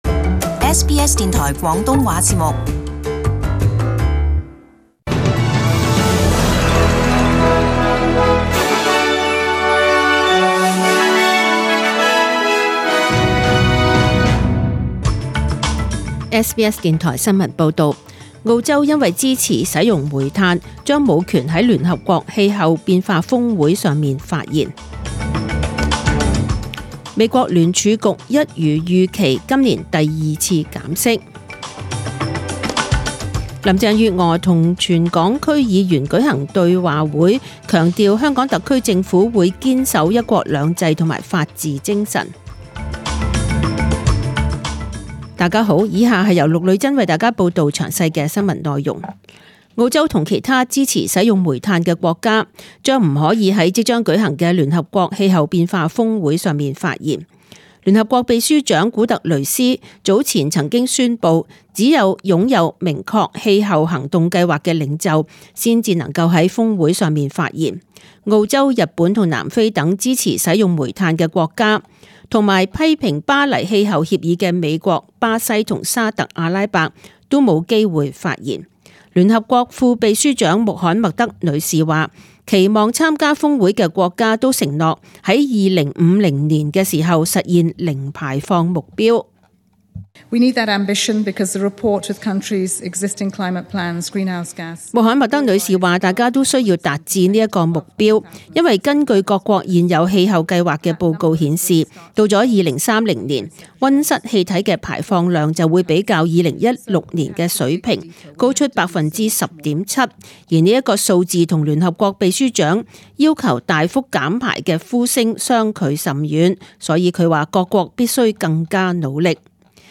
Detailed morning news bulletin